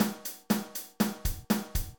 The Basic Drum PatternLet's start with a very straight beat programmed directly into Cubase's drum editor with all note velocities left unchanged (i.e. 100) You can see this in the screenshot on the left.
As you can hear there is a definite robotic feel to the drums and they don't sound particularly lifelike at all.